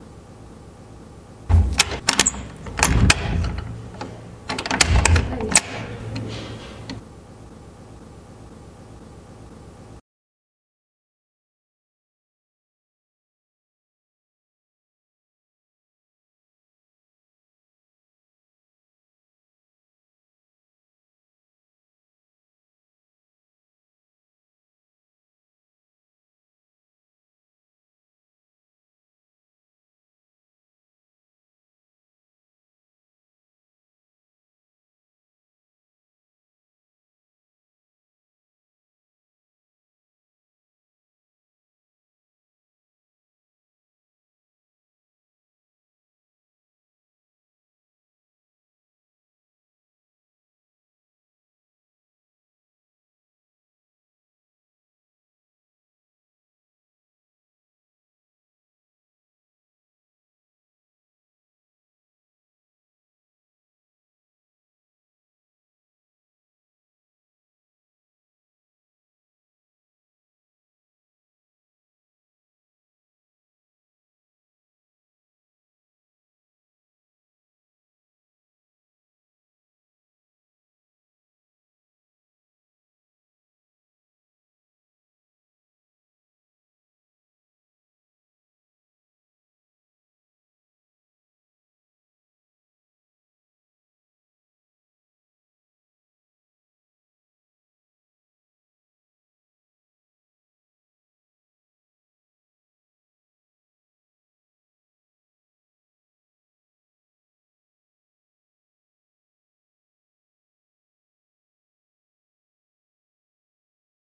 钥匙锁定和解锁抽屉 OWI
描述：使用步枪麦克风录制。钥匙锁和解锁木制抽屉。
Tag: 木抽屉 钥匙开锁 钥匙 抽屉 钥匙锁 OWI